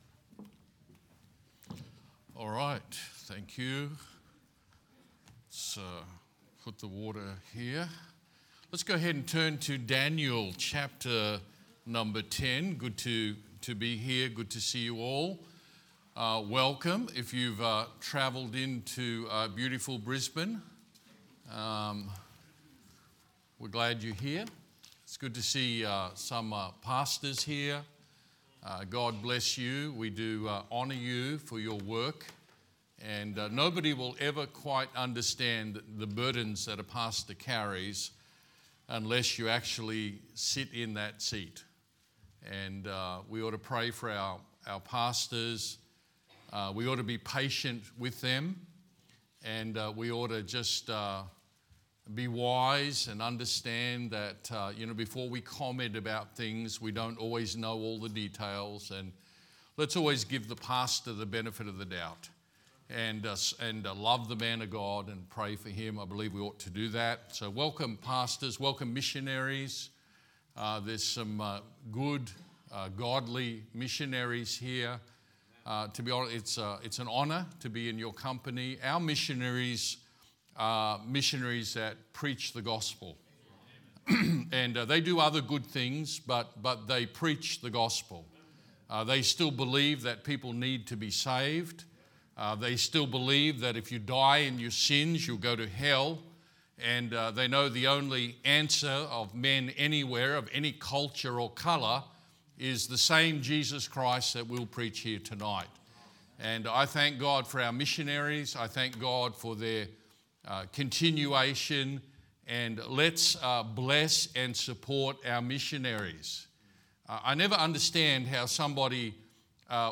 Sermons | Good Shepherd Baptist Church
Leadership Conference 2024